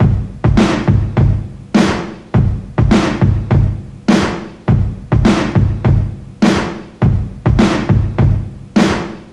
Loops, breaks